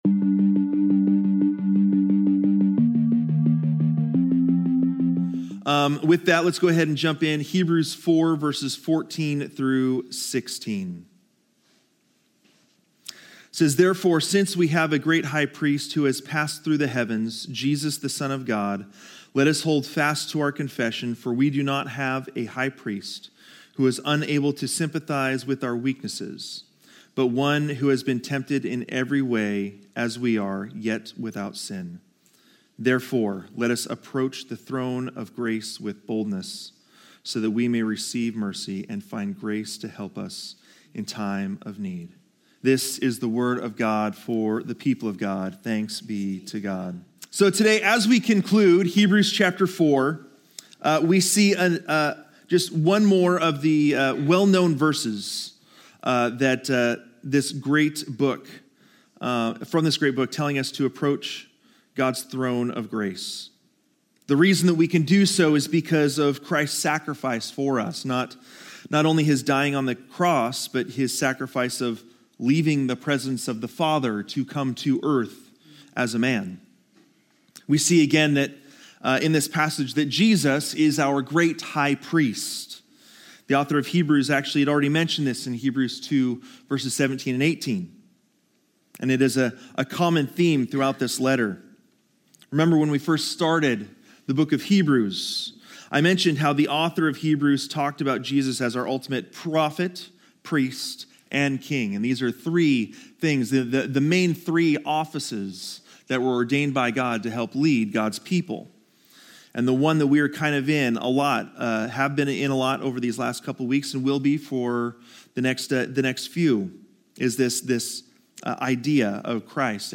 Scripture Passages Used in the Sermon